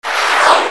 SFX闪白音效下载
SFX音效